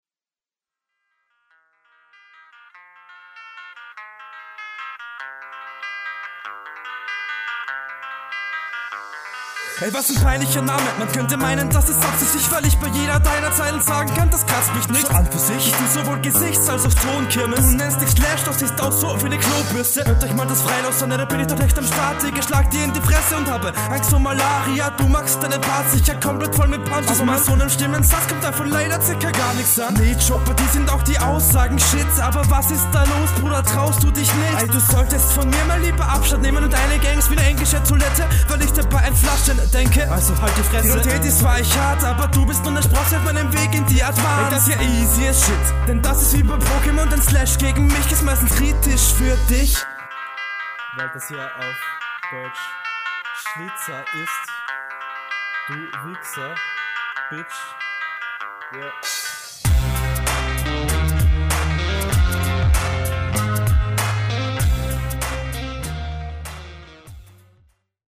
Flowlich eine super gute Steigerung zu dem letzten, was ich von dir kenne.
Sehr chilliger Beat und ein sehr schöner Einstieg, Druckvoll, on point und geht nach vorne, …